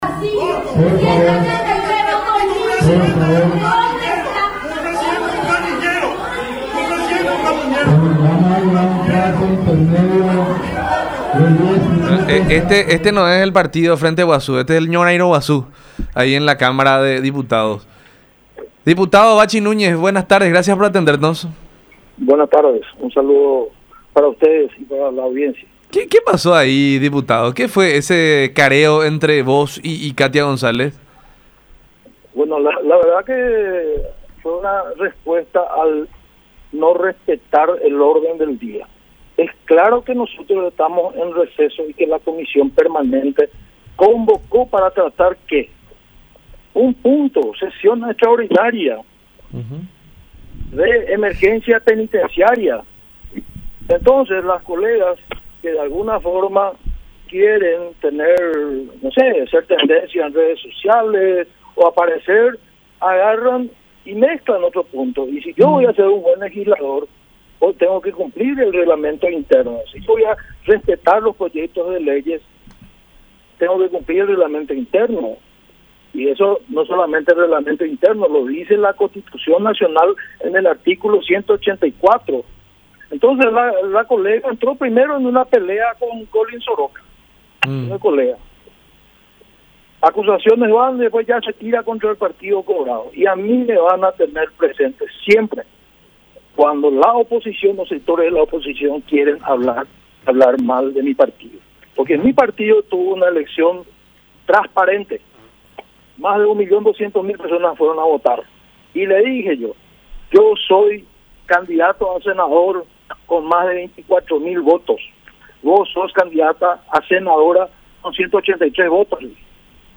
“Ya nos quisieron sacar de la lucha partidaria en las internas. Ahora lo quieren hacer en las generales e involucran a varios colegas congresistas. El embajador hasta se acordó de la convención del Partido Colorado (que habilitó a Cartes para ser candidato a presidente). ¿Por qué los anteriores embajadores no habían hablado ya de eso? No tiene validez lo que dice el embajador, que de alguna manera está influenciado por los informes remitidos desde acá. Sabemos que es un operativo originado desde la oposición y de ciertos referentes del Partido Colorado”, dijo Núñez en diálogo con Buenas Tardes La Unión por Unión TV y radio La Unión, calificando a Ostfield como “vocero de la Concertación”.